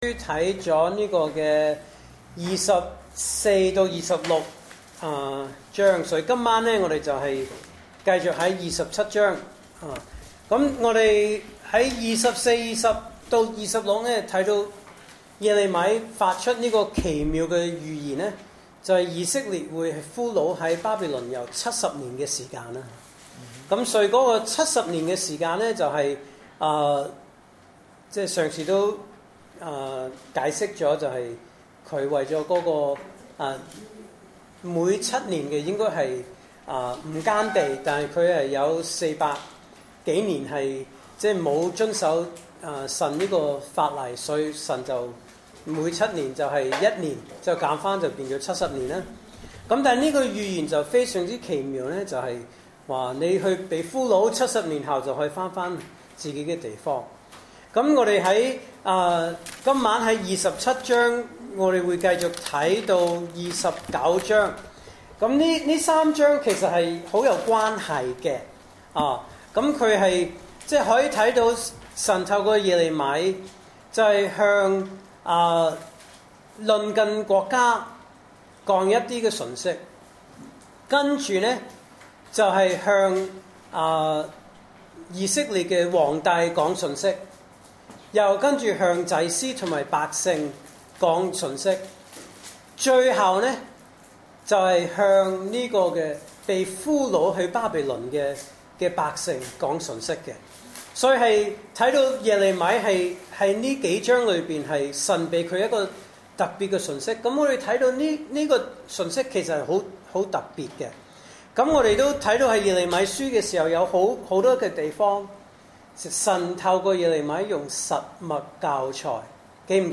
來自講道系列 "查經班：耶利米書"